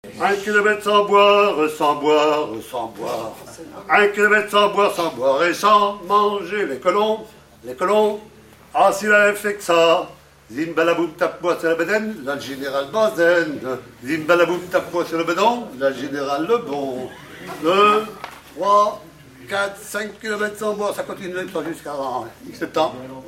Elle provient de Chavagnes-en-Paillers.
voix seule
gestuel : à marcher
Pièce musicale inédite ( chanson, musique,... )